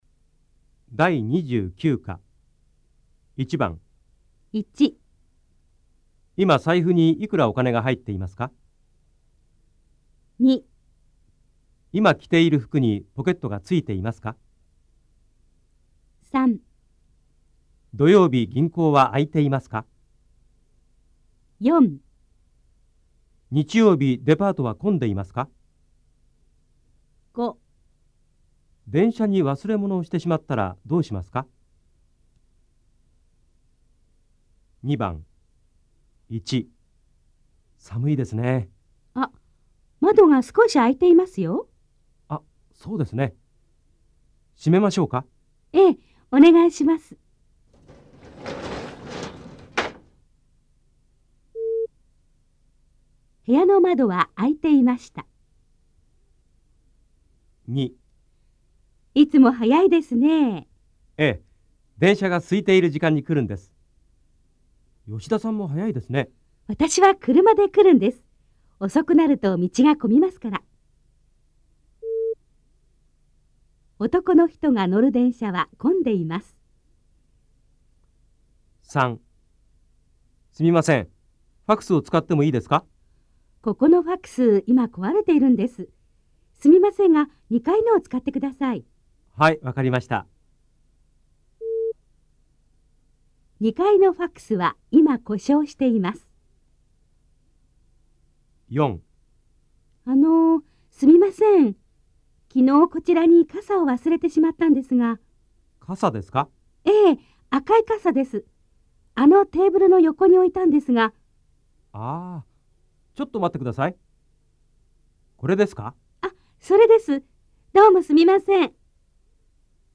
大家的日语-第29课听力练习